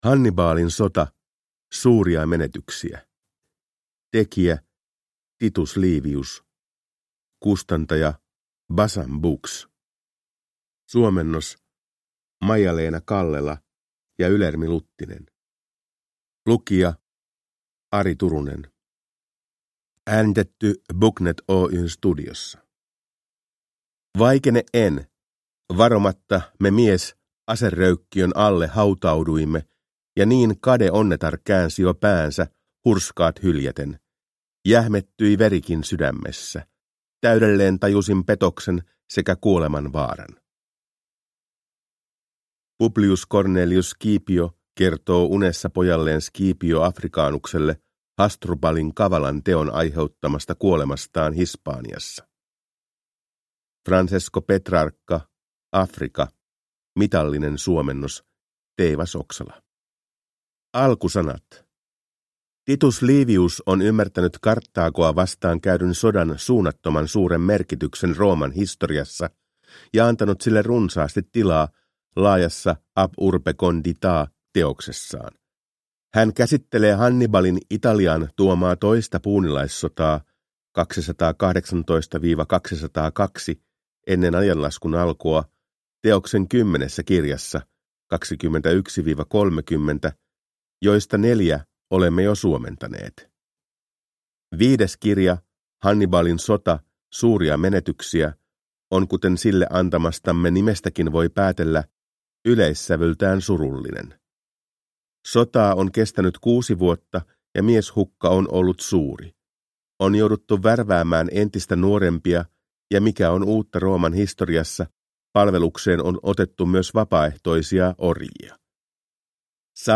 Hannibalin sota. Suuria menetyksiä – Ljudbok